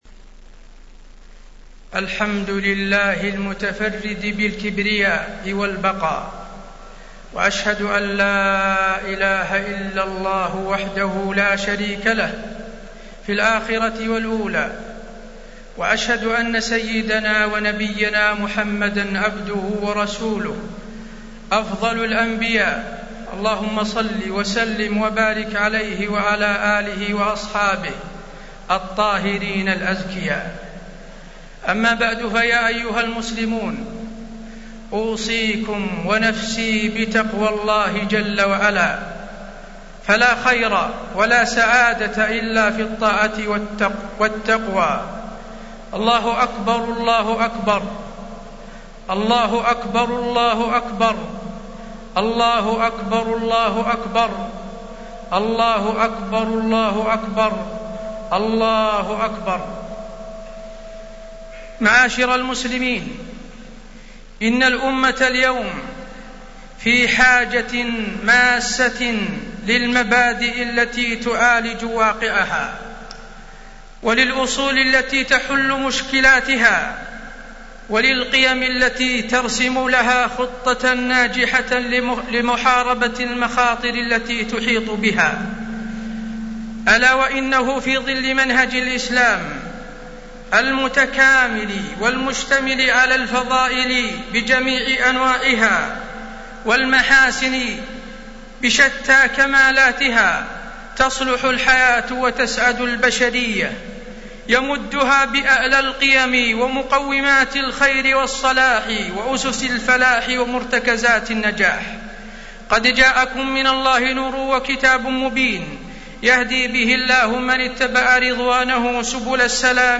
خطبة عيد الفطر- المدينة - الشيخ حسين آل الشيخ
المكان: المسجد النبوي